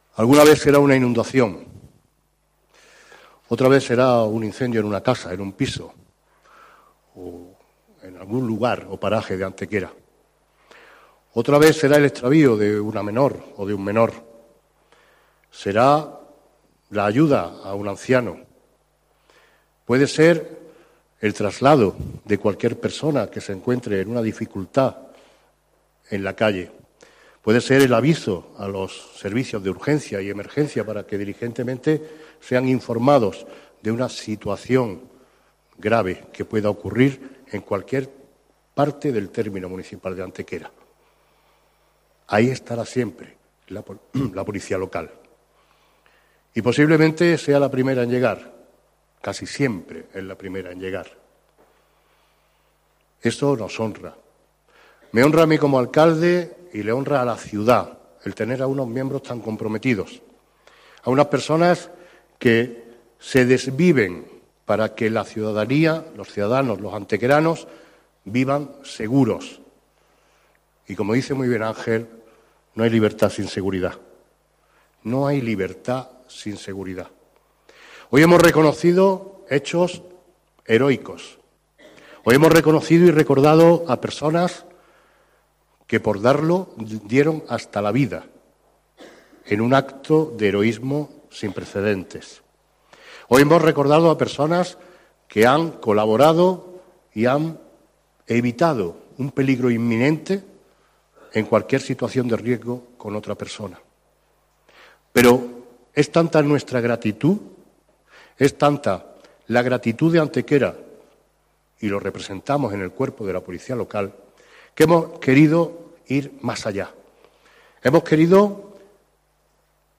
Celebrados los actos conmemorativos de la festividad del Patrón de la Policía Local en Antequera con entrega de condecoraciones y celebración de una eucaristía
Cortes de voz